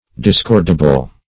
Search Result for " discordable" : The Collaborative International Dictionary of English v.0.48: Discordable \Dis*cord"a*ble\, a. [Cf. OF. descordable.]